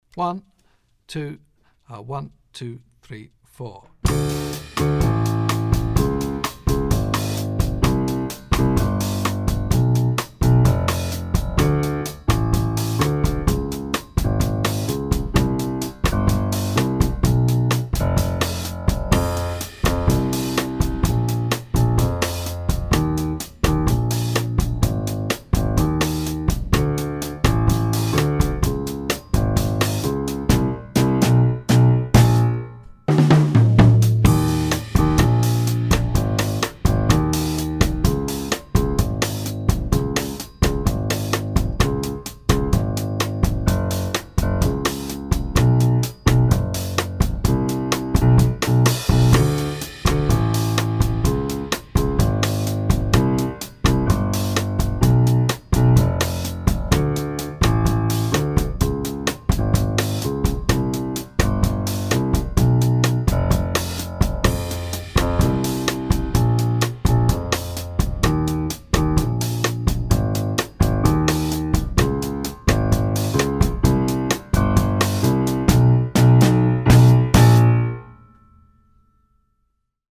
25-Latin-Comping-Bossa-Bass-Line.mp3